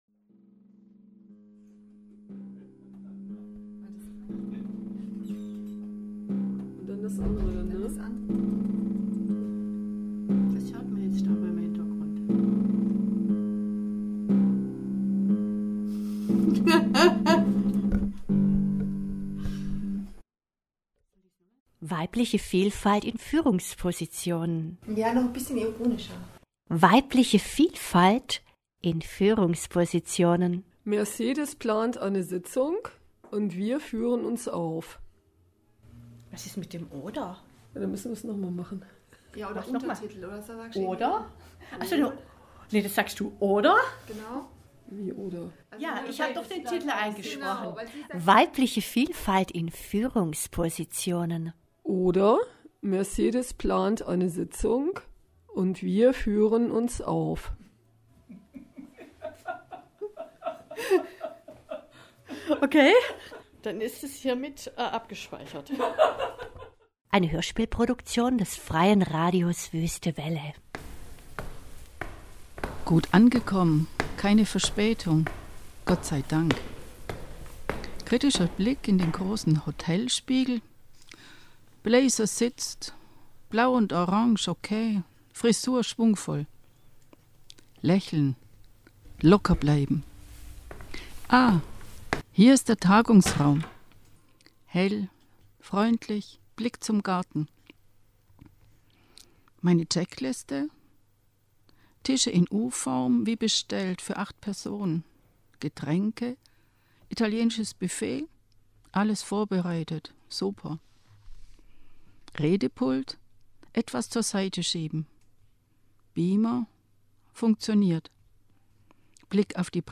Freies Radio Wüste Welle - Hörspiele aus der Werkstatt - Die Hörspieltage
Nun gut, für die Geräusche mussten wir nochmals nachlegen ...